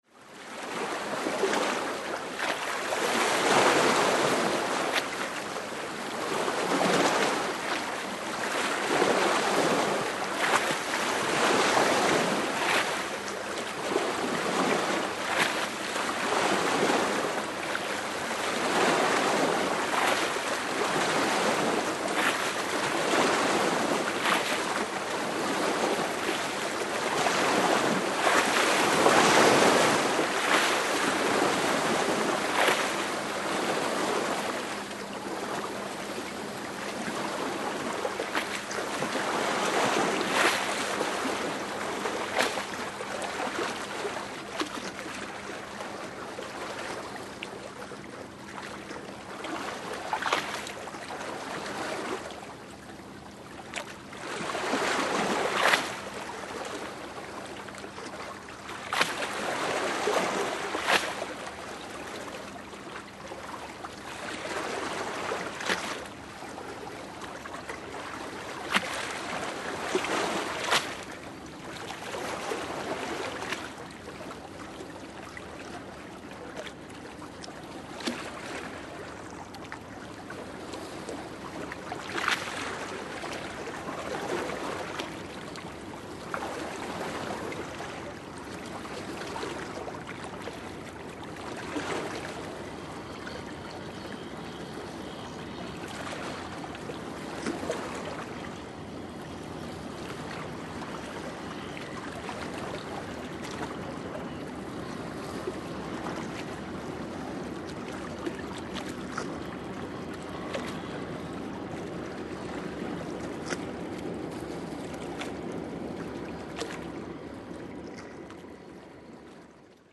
Звуки кораблей
Шум воды у причала